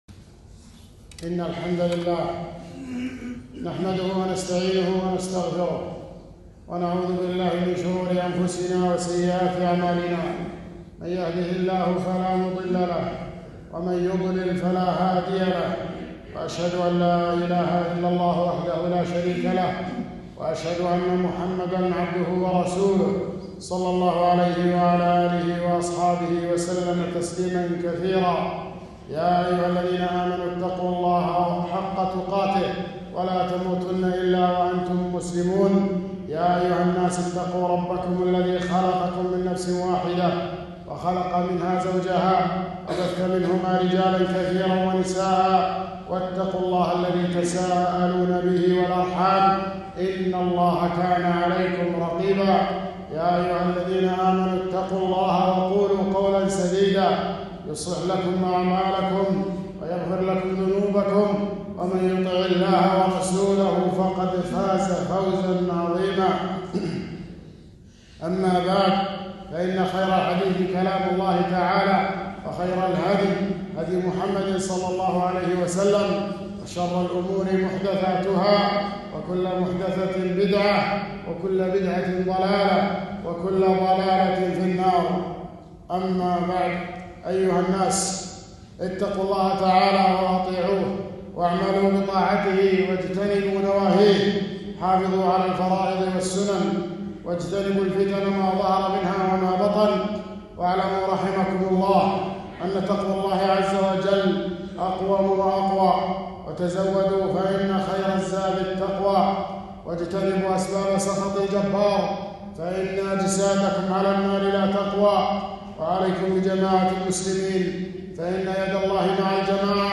خطبة - فضل يوم عرفة أحكام الأضحية